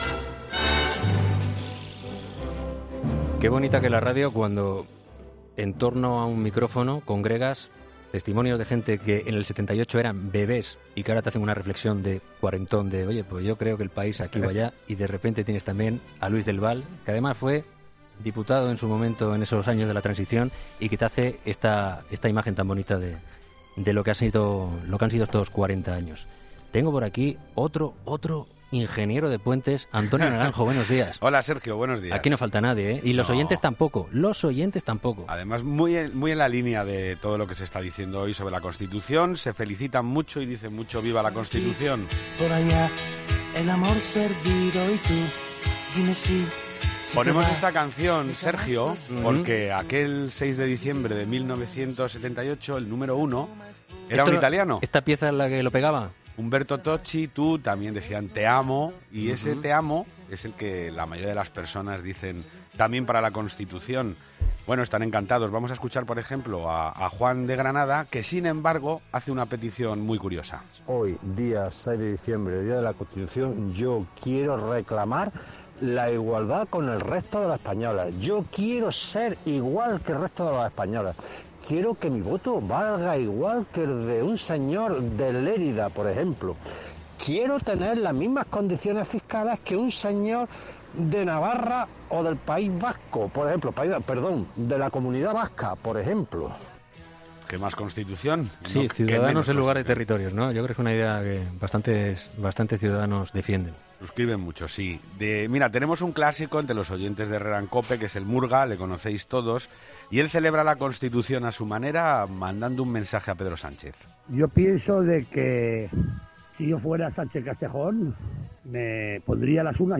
La tertulia de los oyentes es el espacio en el que los seguidores de Carlos Herrera se convierten en un tertuliano más y proponen temas de actualidad que no han salido en la tertulia.